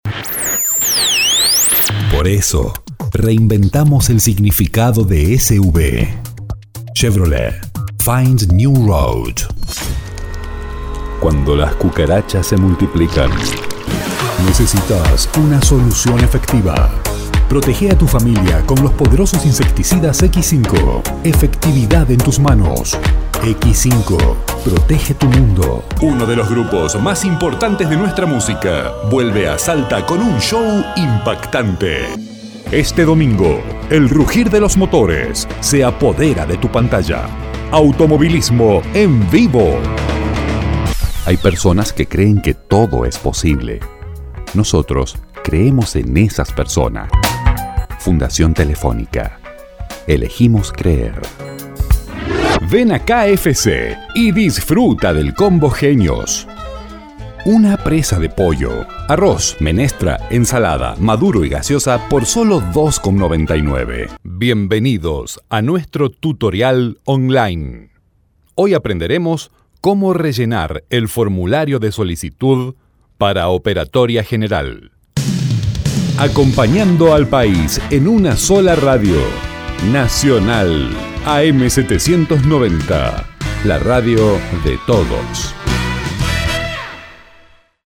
Locutor argentino, español neutro,voz Senior,
Sprechprobe: Werbung (Muttersprache):